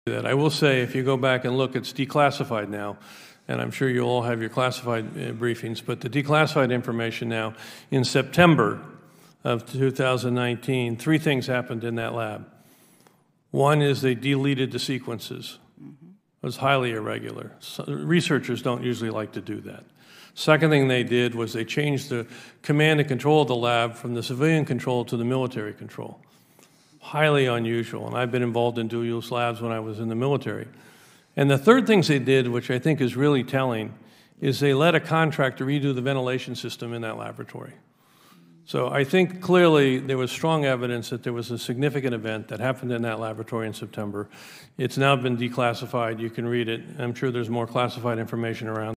Dr. Robert Redfield, ehemaliger CDC-Direktor, spricht über drei verdächtige Ereignisse, die im September 2019 im Labor in Wuhan stattfanden: